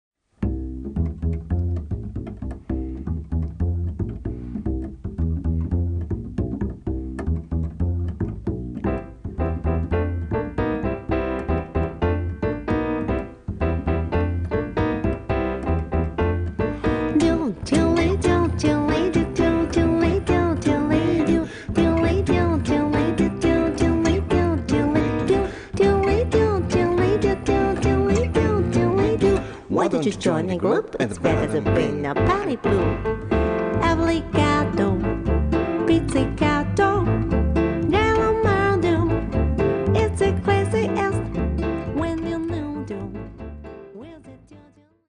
guitar / vocal
bass
piano / rhodes / melodica